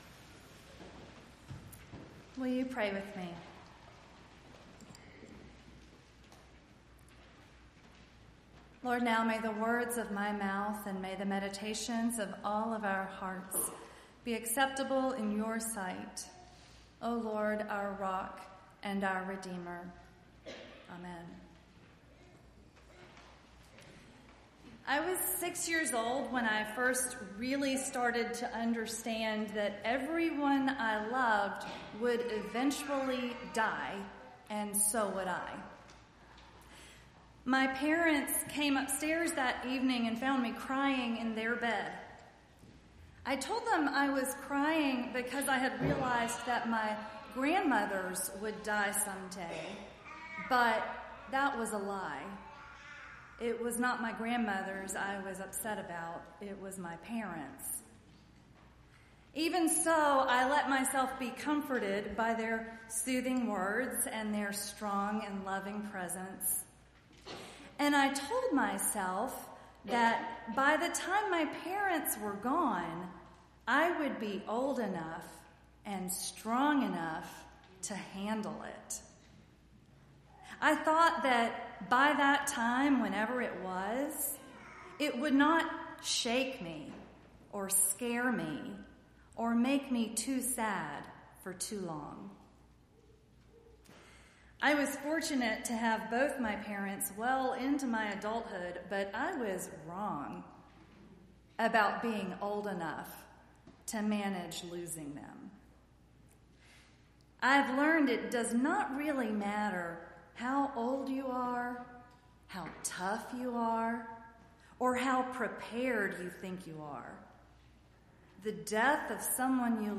11-3-19-sermon.mp3